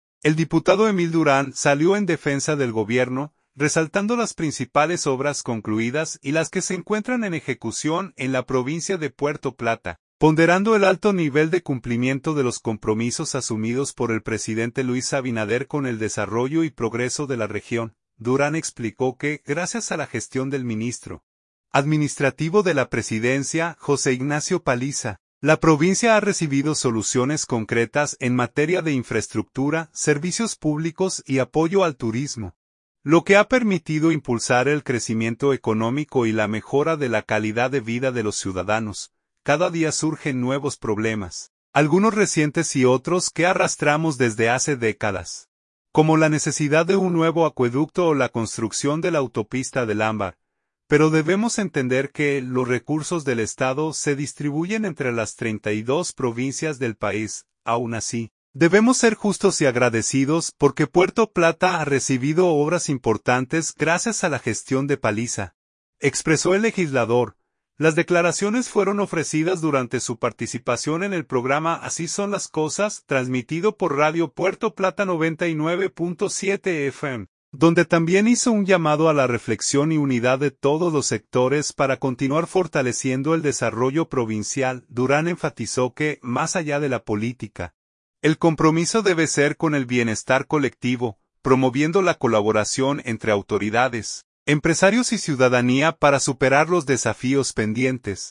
Las declaraciones fueron ofrecidas durante su participación en el programa “Así son las cosas”, transmitido por Radio Puerto Plata 99.7 FM, donde también hizo un llamado a la reflexión y unidad de todos los sectores para continuar fortaleciendo el desarrollo provincial.